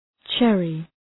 Προφορά
{‘tʃerı}